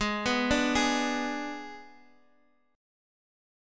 G#dim chord